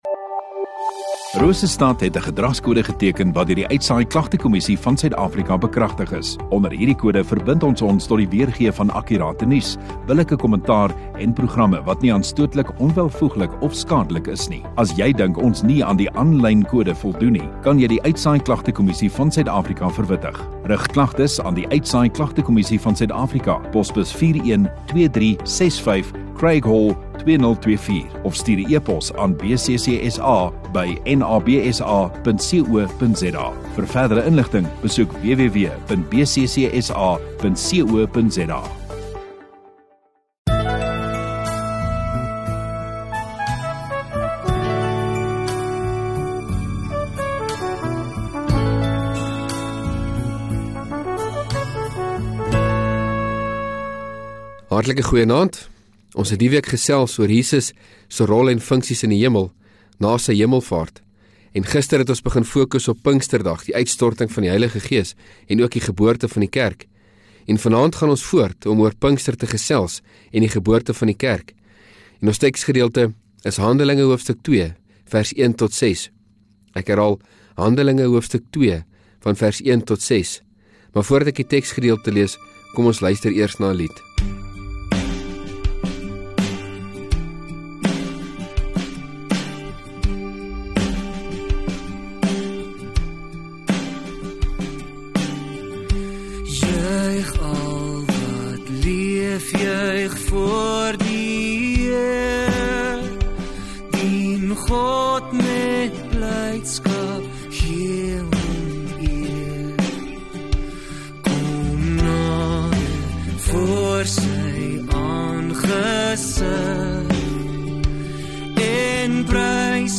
8 Jun Sondagaand Erediens